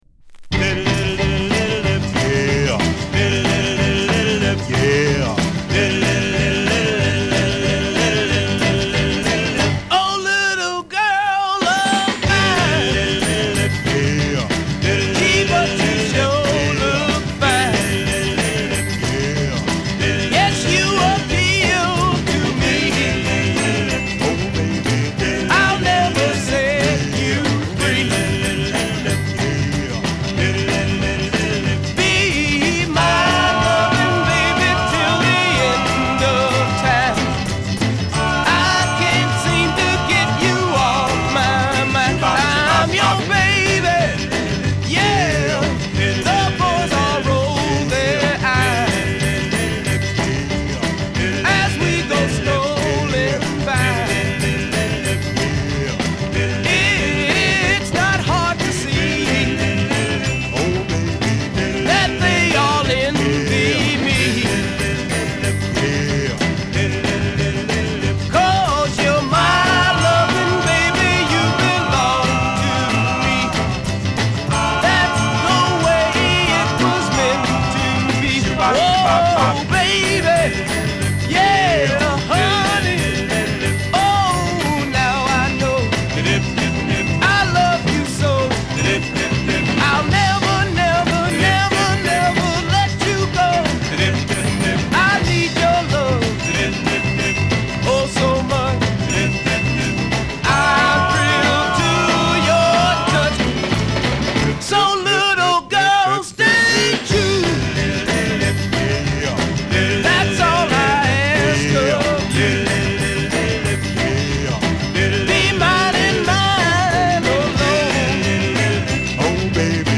原曲を遥かに上回るラフなヴォーカルとスピーディーな演奏で駆け抜ける痛快ヴァージョン。